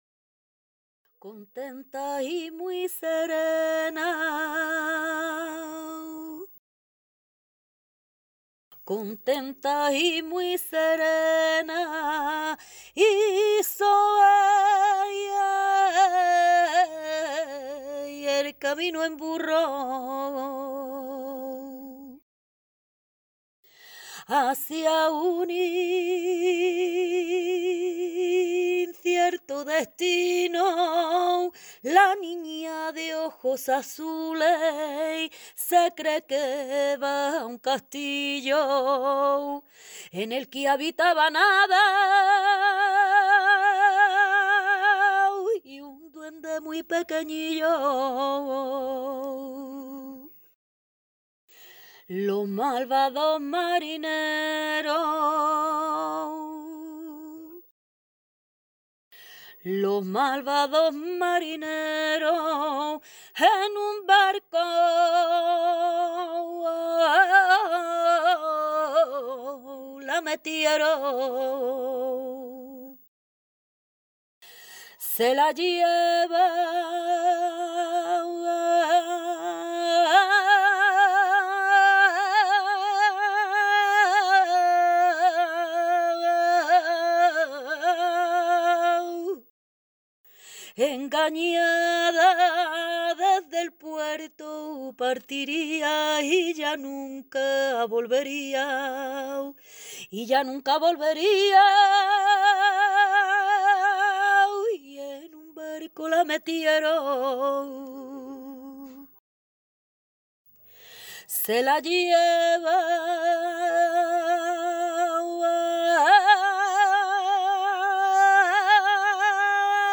Flamenco clásico: COLOMBIANAS